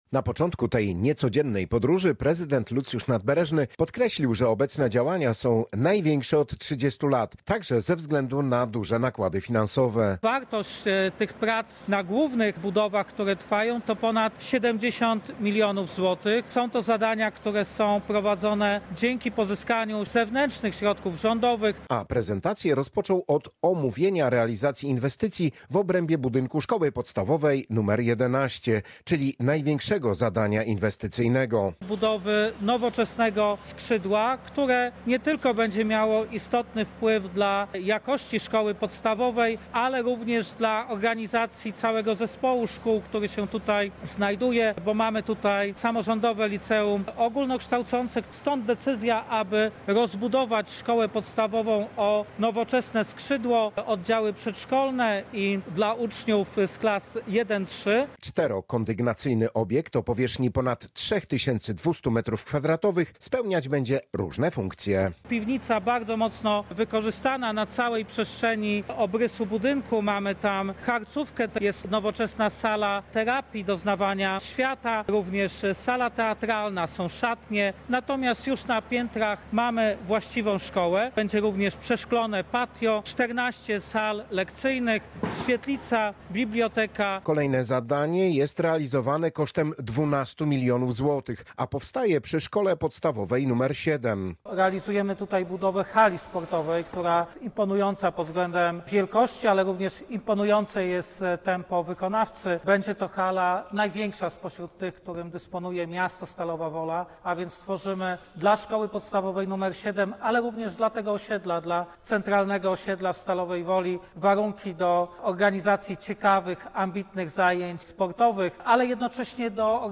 -Powstanie bardzo nowoczesny obiekt, który będzie miał ponad 3200 metrów kwadratowych. To 4 piętrowy budynek, który połączy wygodę z funkcjonalnością – powiedział Lucjusz Nadbereżny, prezydent Stalowej Woli.
Relacja